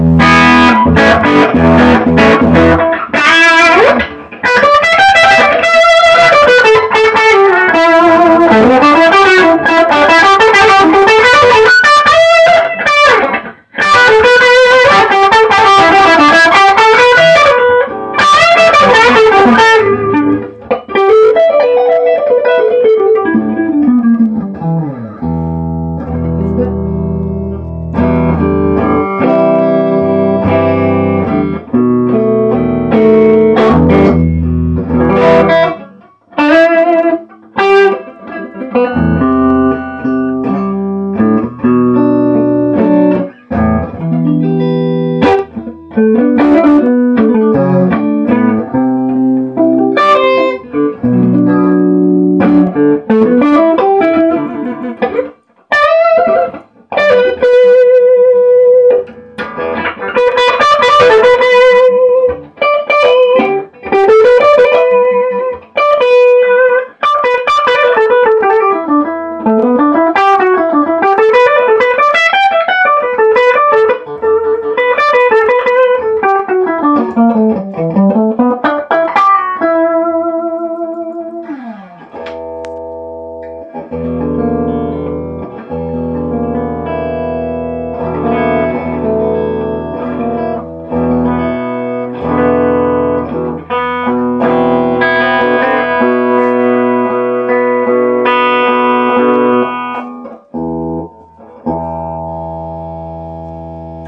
Uusi 2-kanavainen Rikstone H15 VM nuppi, jossa toinen kanava pohjautuu VOX AC15 EF86-kanavaan ja toinen Matchless Spitfireen.
Soundinäytteet (Gibson Les Paul äänitetty älypuhelimella):